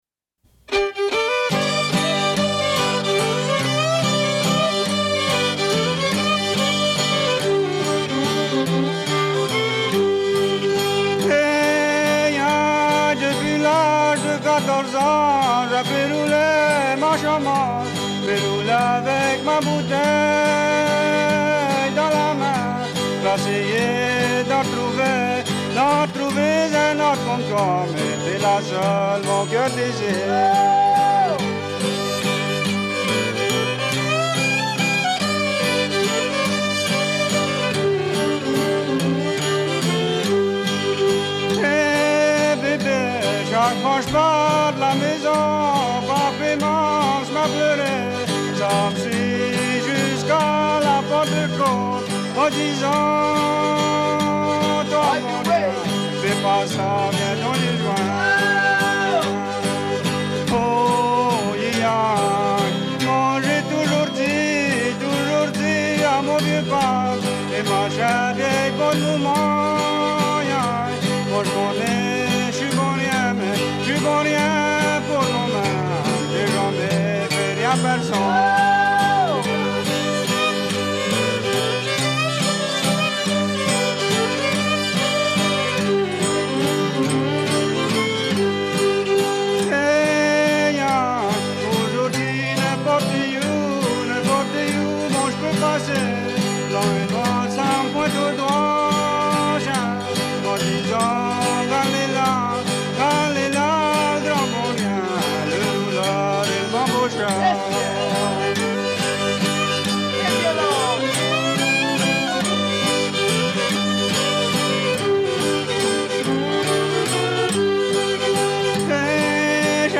Cajun Music